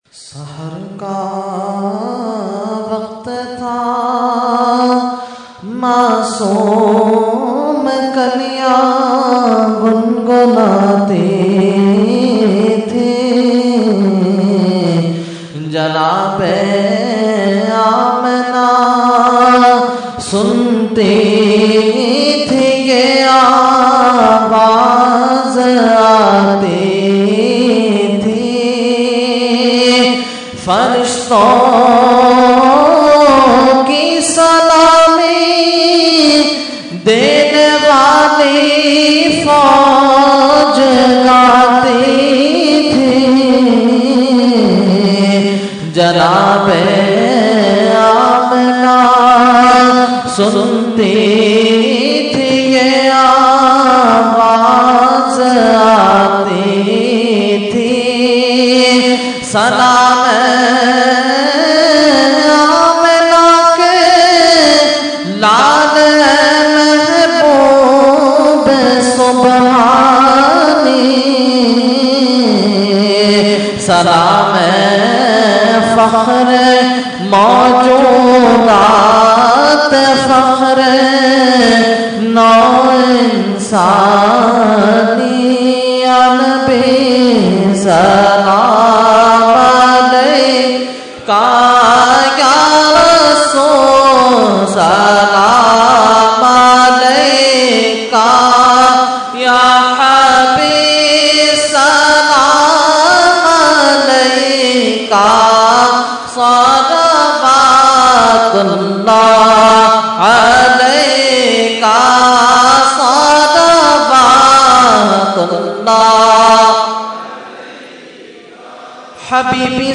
Category : Salam | Language : UrduEvent : Subhe Baharan 2015